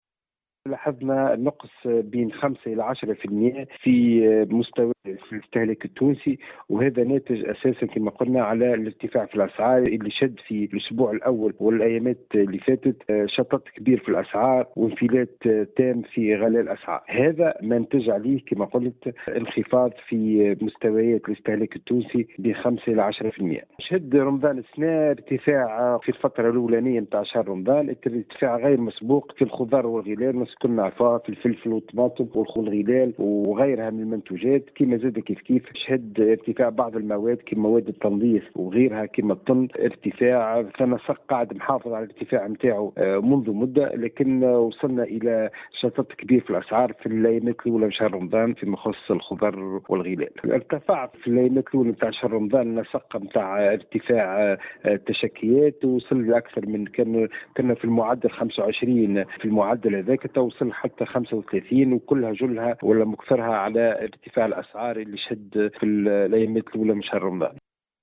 تصريح للجوهرة أف أم